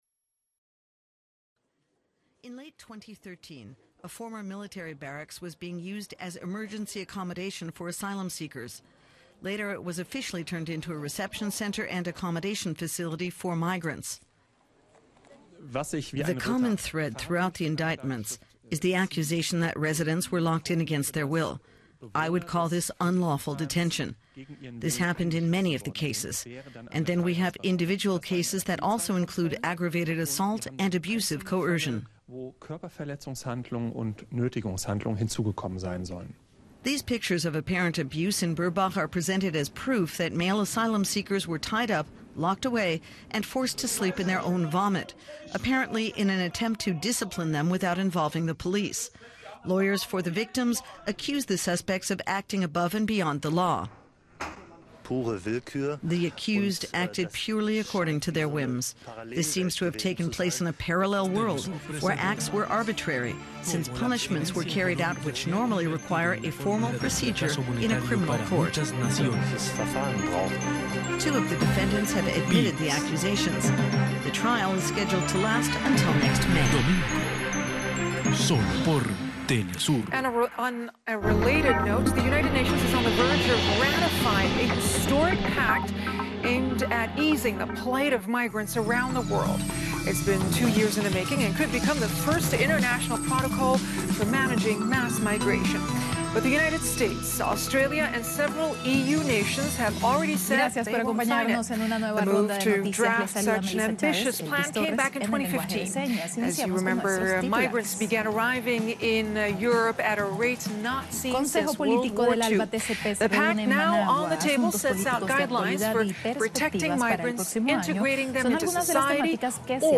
collage montaje non-music soundcollage soundart_2